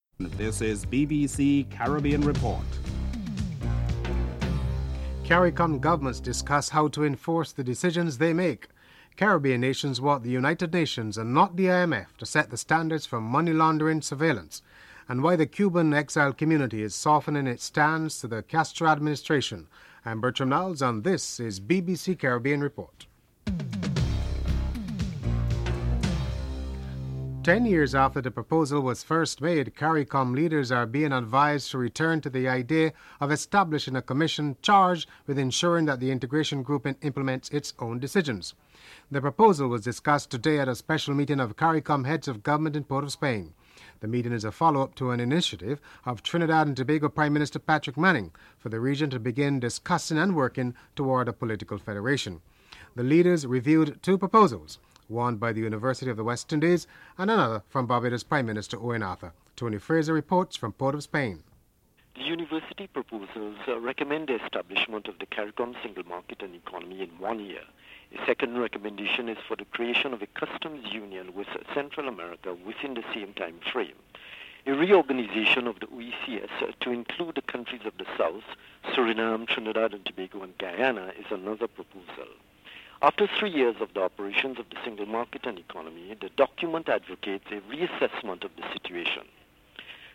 Ralph Gonzalves, Prime Minister of Saint Vincent is interviewed
David Blunkett, Home Secretary describes the incident as a real and serious threat.